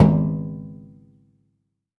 metal_drum_impact_thud_01.wav